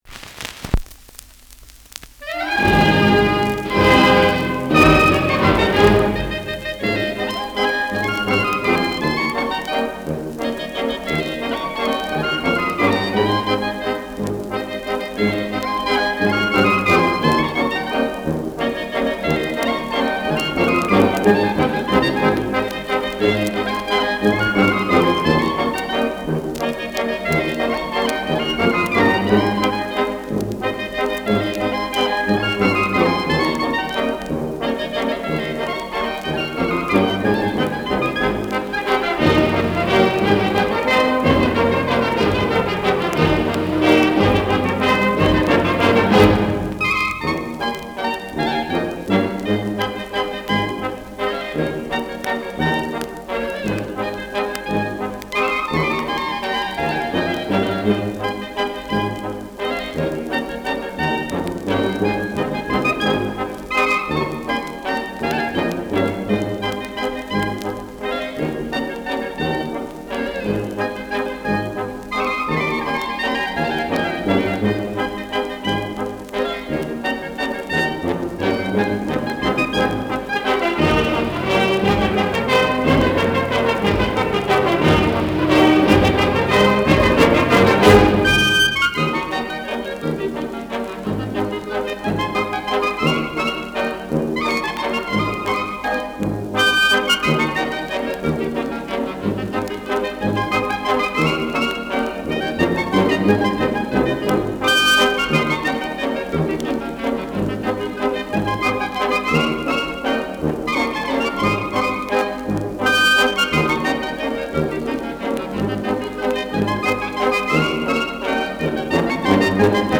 Schellackplatte
Tonrille: Kratzer 6 Uhr Leicht
Kapelle Hallertau (Interpretation)
[Vohburg an der Donau] (Aufnahmeort)